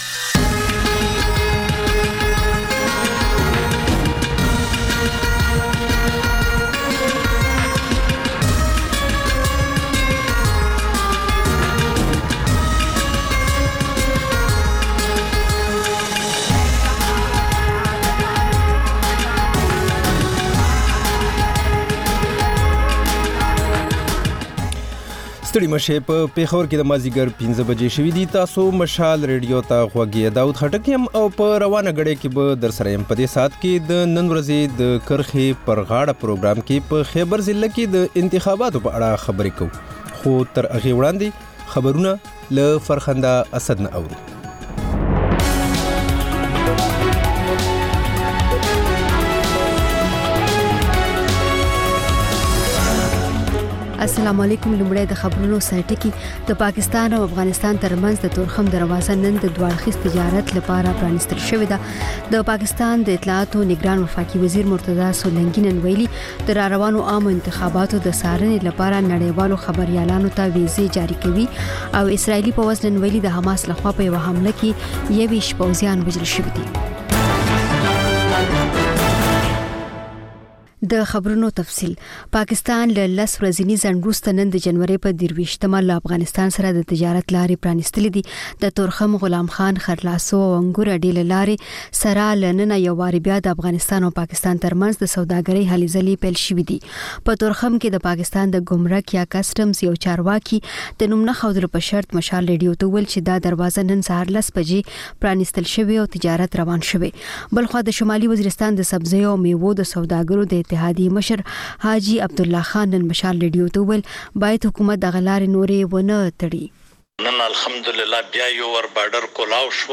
د مشال راډیو ماښامنۍ خپرونه. د خپرونې پیل له خبرونو کېږي، بیا ورپسې رپورټونه خپرېږي.